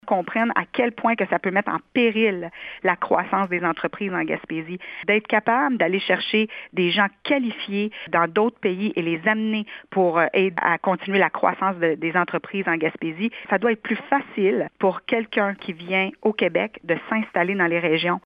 Nadia Minassian explique que le document dresse une liste des secteurs que le gouvernement devrait prioriser pour développer les régions et ainsi offrir des perspectives d’avenir.